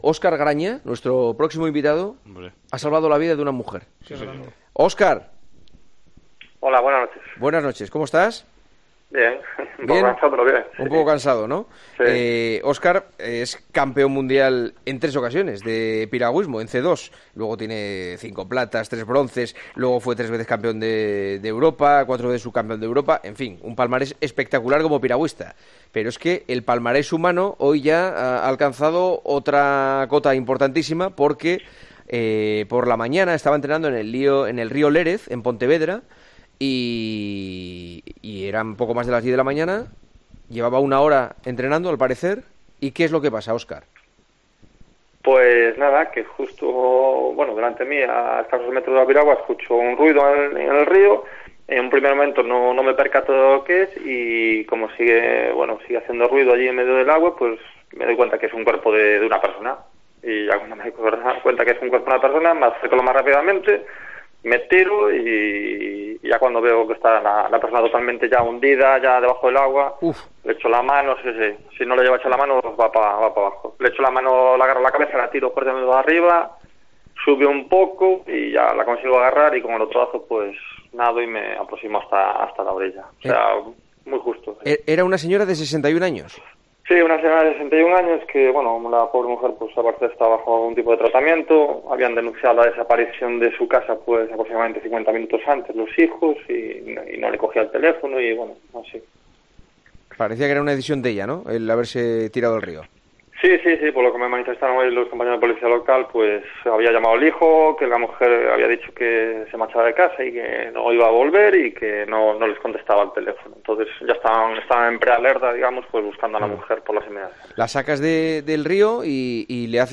Juanma Castaño charló con en El Partidazo de COPE con este palista y Policía Nacional que ha vuelto a ser noticia por salvar la vida de una mujer que se tiró al río: "Me dijo que se quería morir.